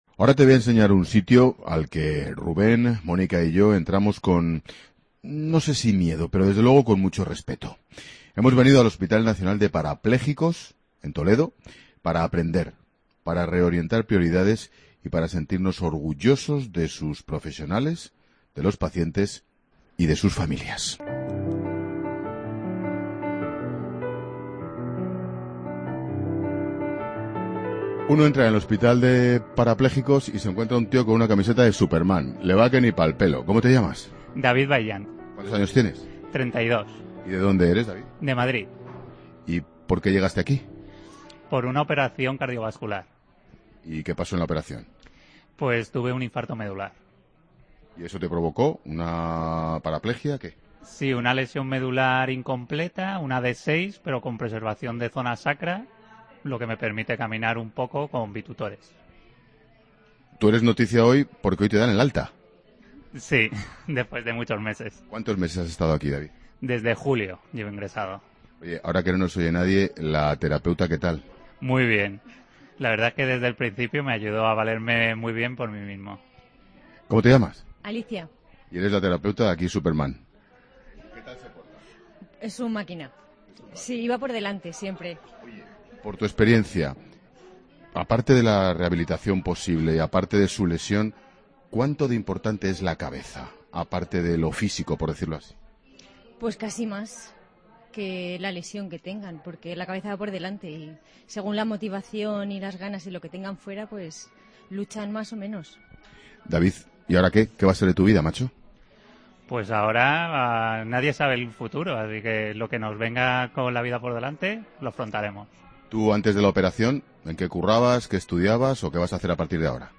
AUDIO: Escucha el reportaje completo de Ángel Expósito desde el Hospital Nacional de Parapléjicos de Toledo en 'La Tarde'